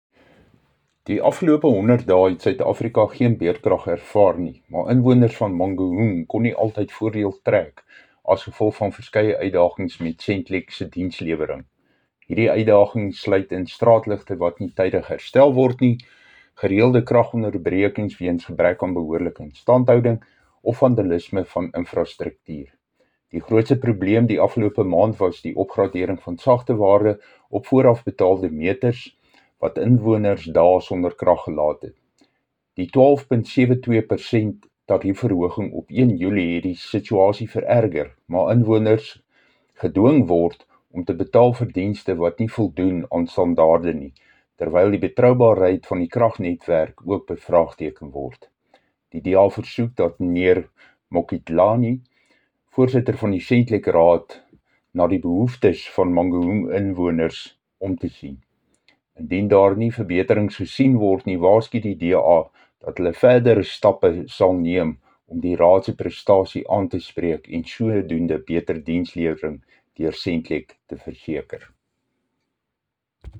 Afrikaans soundbites by Cllr Dirk Kotze